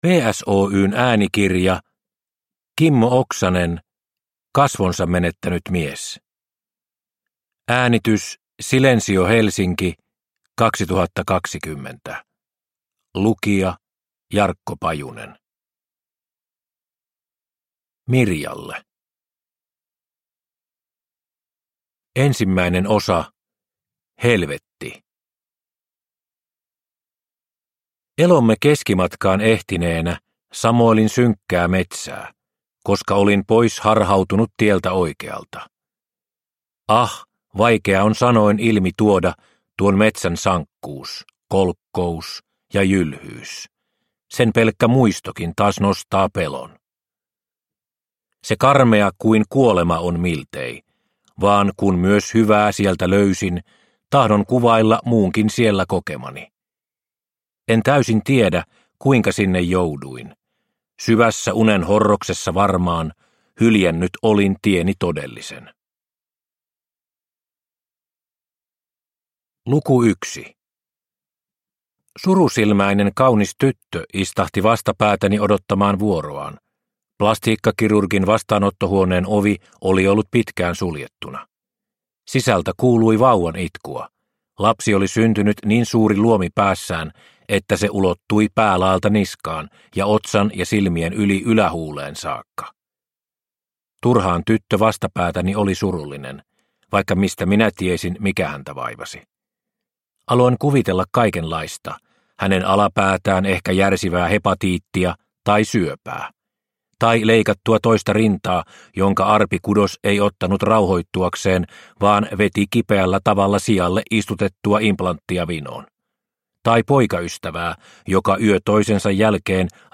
Kasvonsa menettänyt mies – Ljudbok